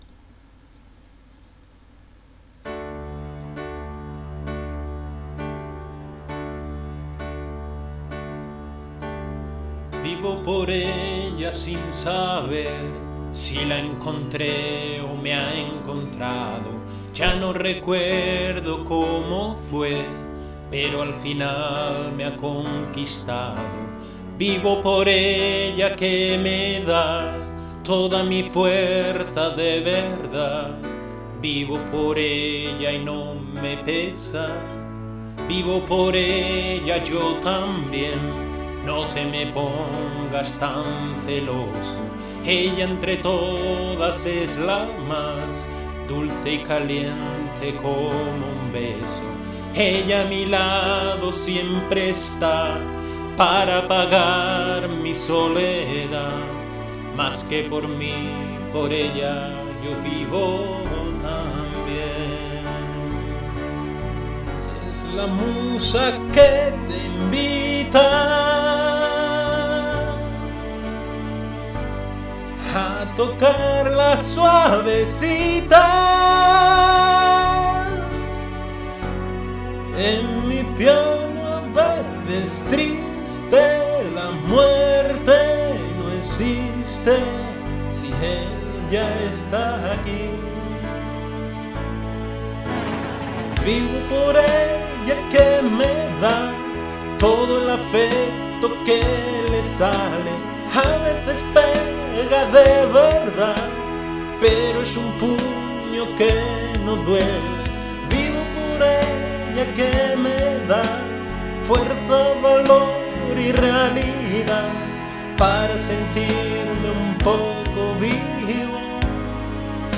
Me gusta cantar, pero sólo como aficionado.